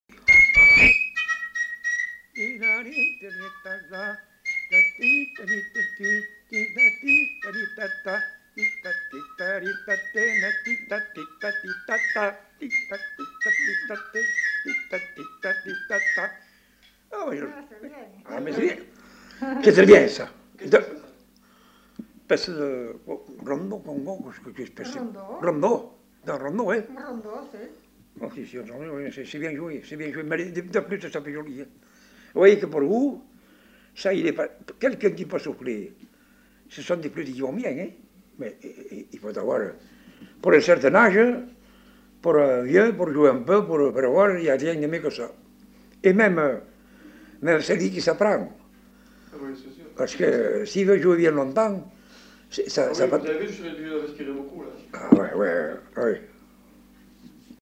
Aire culturelle : Bazadais
Genre : morceau instrumental
Instrument de musique : flûte à trois trous
Danse : congo